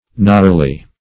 Gnarly \Gnarl"y\, a.
/nar'lee/, adj.